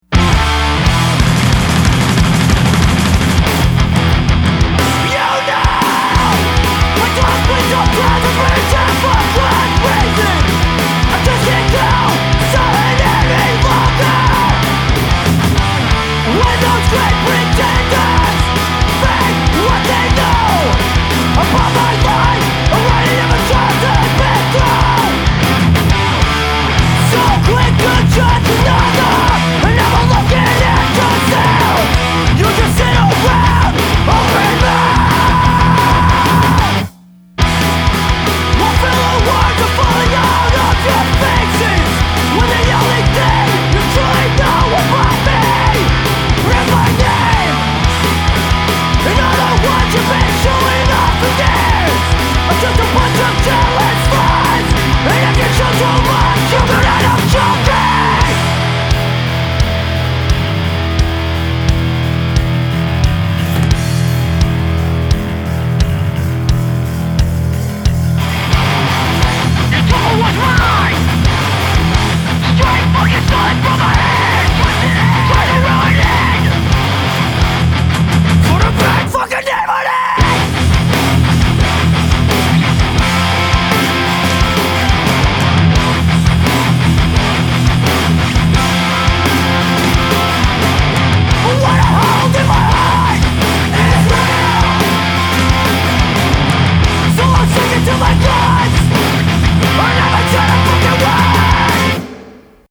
멜로딕 유쓰 크루 좋아하시는 분들은 체크
제가 들어봤던 이 쪽 계열 어떤 밴드들보다도 힘이 넘치네요!
많이 화난거 같은데. ..